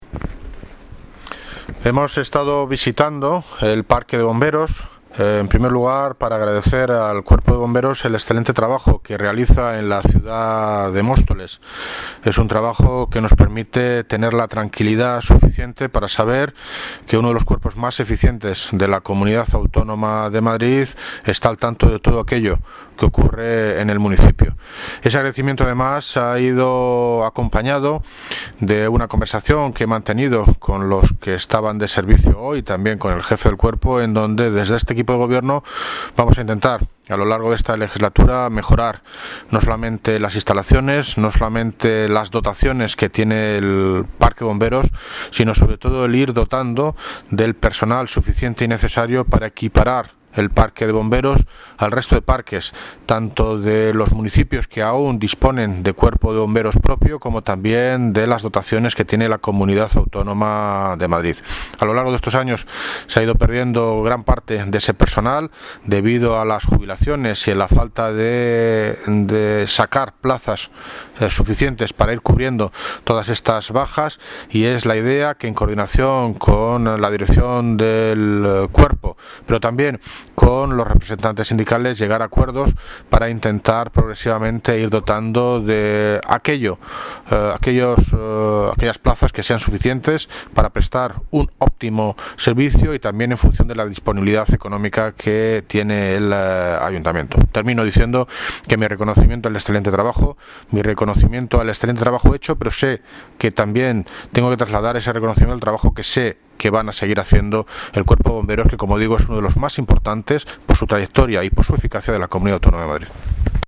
Audio - David Lucas Parrón (Alcalde de Móstoles) Sobre visita Bomberos